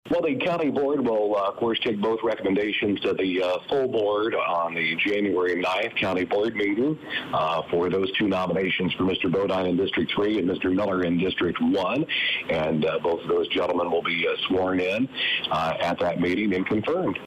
County Board Chairman Larry Baughn says this should all become official at the next County Board meeting.